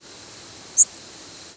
Northern Waterthrush Seiurus noveboracensis
Flight call description A buzzy, distinctly rising "zweedt". A lazy, relatively low-pitched note becoming louder and buzzier at the end.
Bird in flight.
Similar to Louisiana Waterthrush but more distinctly rising, softer at the beginning, and with a finer, drier buzz.
The frequency track was single or double-banded and rising.